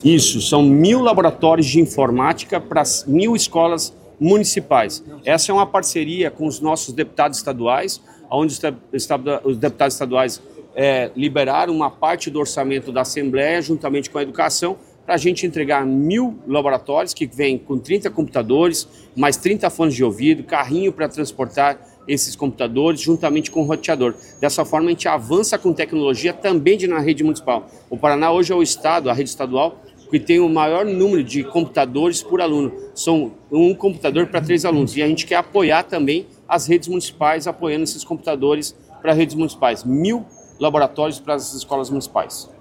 Sonora do secretário da Educação, Roni Miranda, sobre o anúncio de R$ 63,5 milhões em kits tecnológicos para escolas municipais